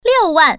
Index of /mahjong_paohuzi_Common_test/update/1658/res/sfx/putonghua/woman/